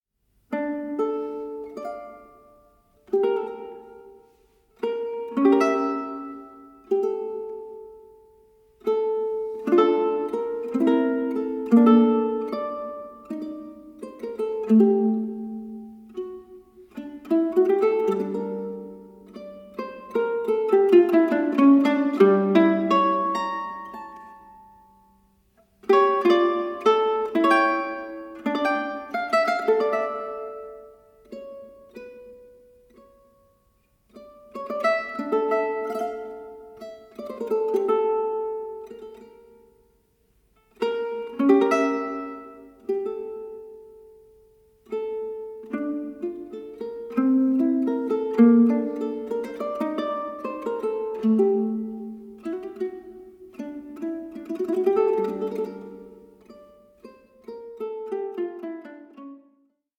FEATURING MANDOLINS, MANDOLA, LUTE AND BAĞLAMA